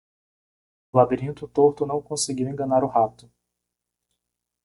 Pronounced as (IPA) /la.biˈɾĩ.tu/